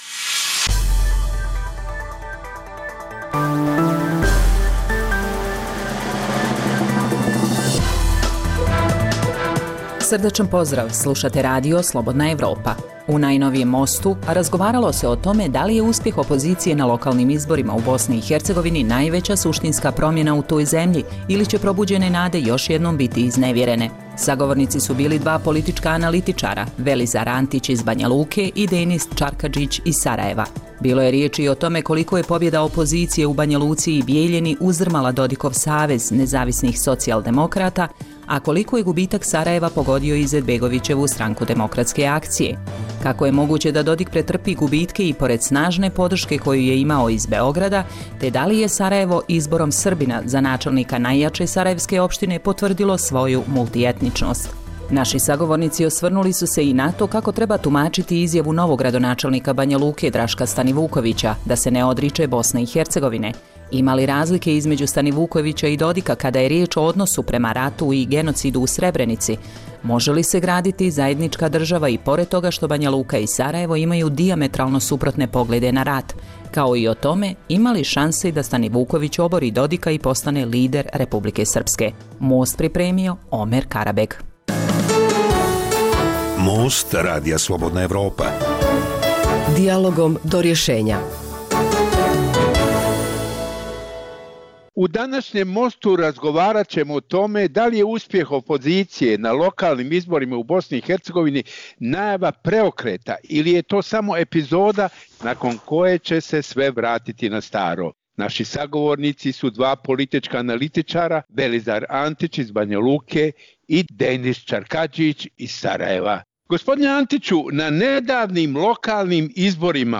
Dijaloška emisija o politici, ekonomiji i kulturi